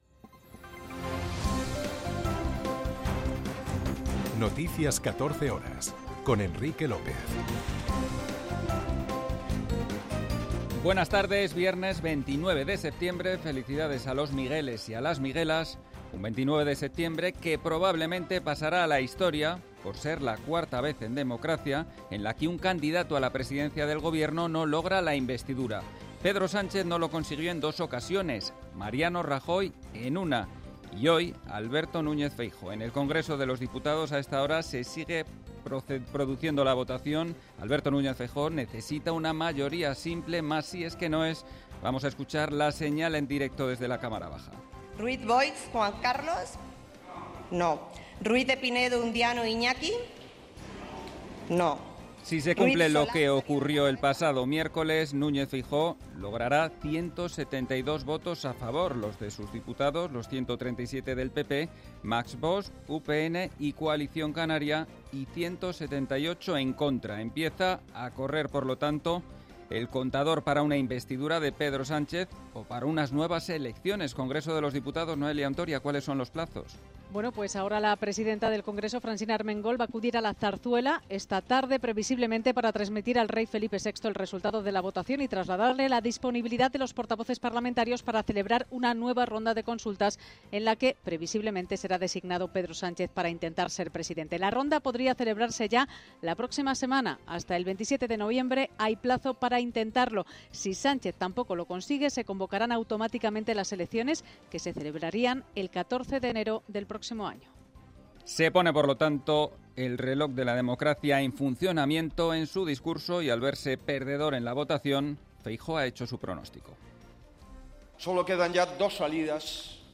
Noticias 14 horas 29.09.2023